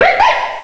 pokeemerald / sound / direct_sound_samples / cries / zorua.aif
-Replaced the Gen. 1 to 3 cries with BW2 rips.